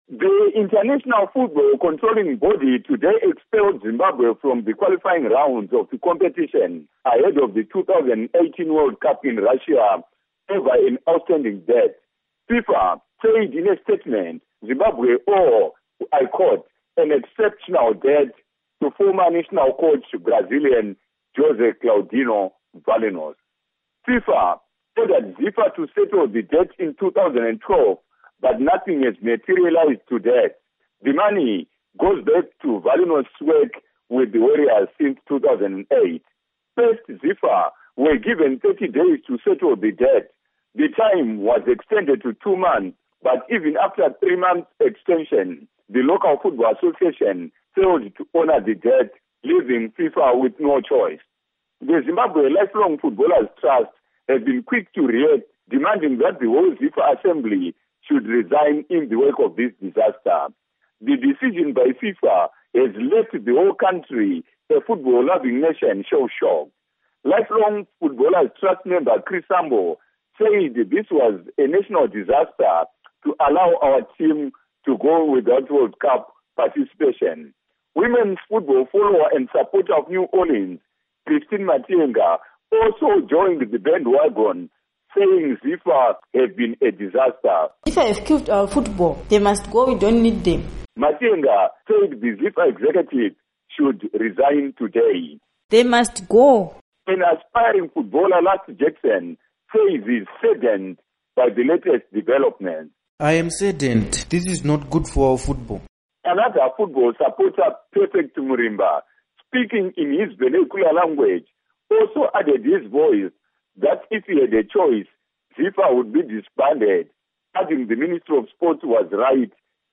Report on ZIFA Soccer Ban